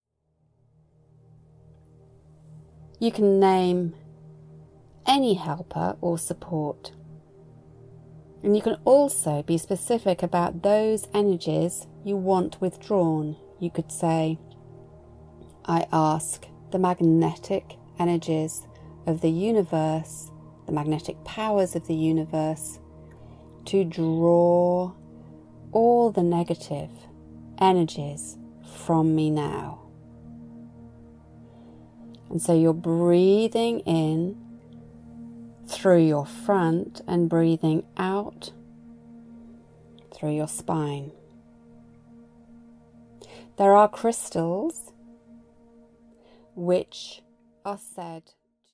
Here is a little talk with some suggestions, and a short meditation in which we open the spine to magnetic forces that can draw away from us what is not needed.